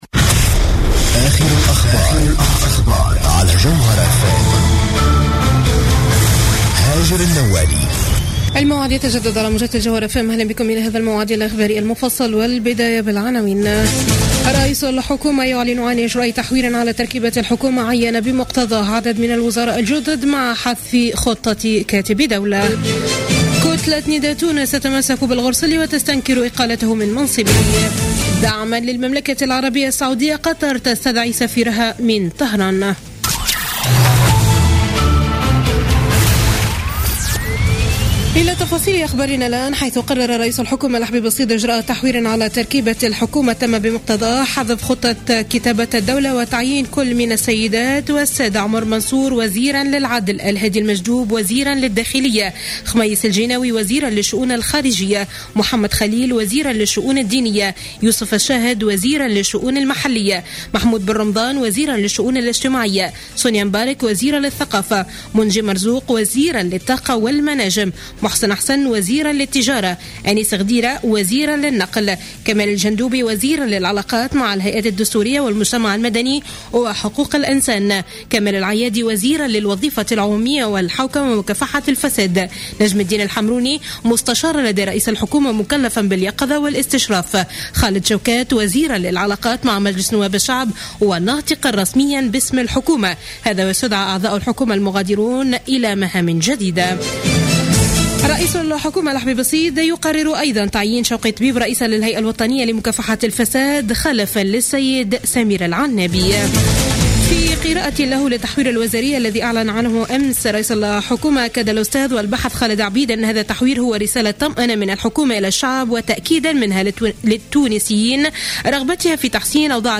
نشرة أخبار منتصف الليل ليوم الخميس 7 جانفي 2016